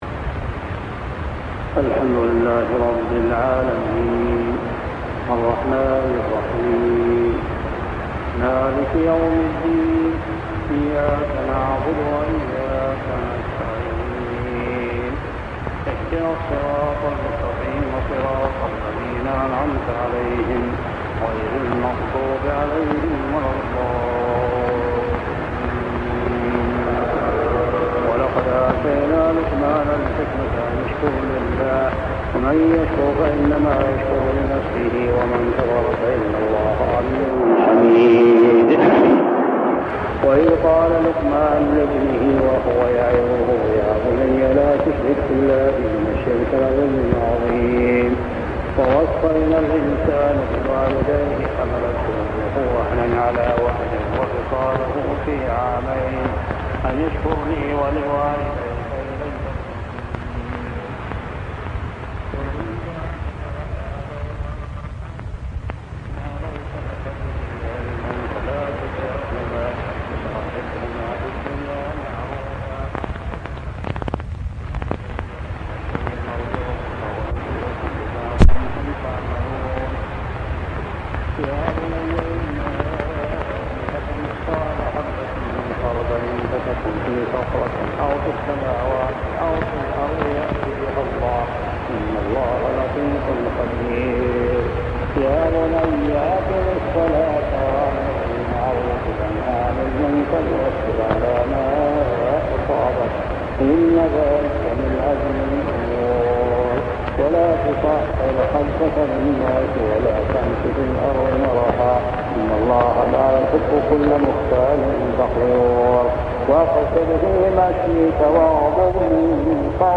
صلاة التراويح عام 1401هـ سور لقمان 12-34 و السجدة كاملة و الأحزاب 1-27 | Tarawih Prayer Surah Al-Luqman, As-Sajdah, Al-Ahzab > تراويح الحرم المكي عام 1401 🕋 > التراويح - تلاوات الحرمين